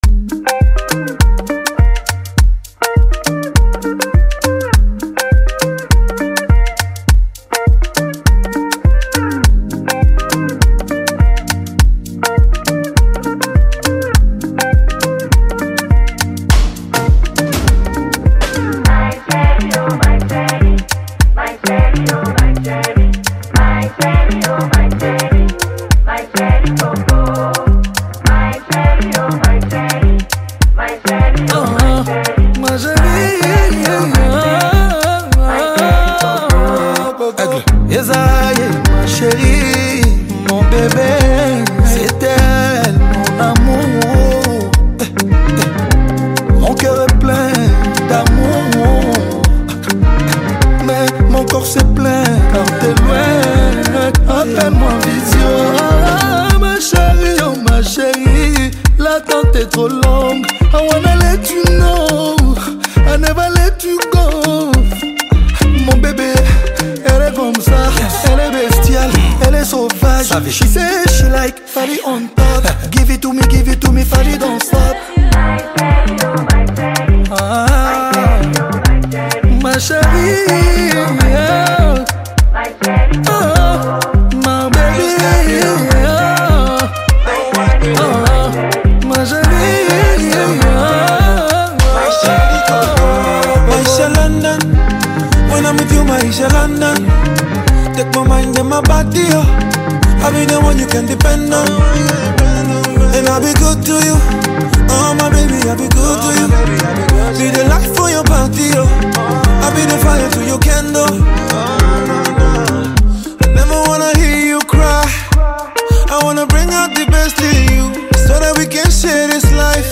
creating a unique and captivating sound.